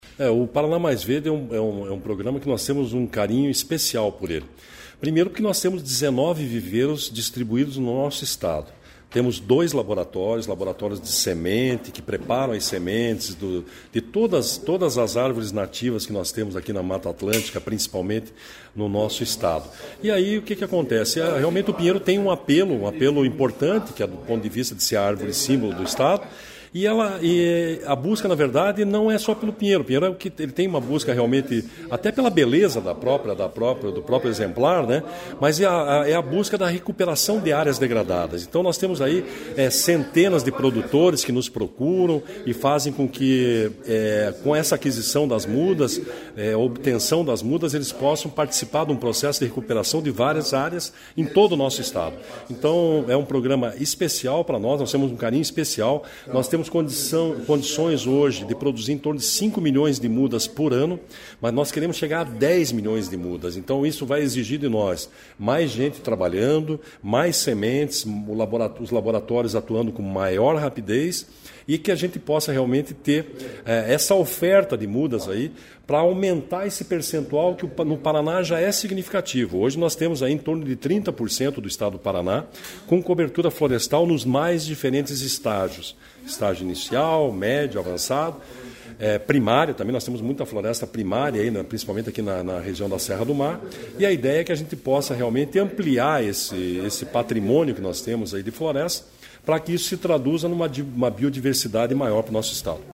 Sonora do Diretor-presidente do IAT, Everton Souza, sobre procura por mudas de Araucária nos viveiros do Estado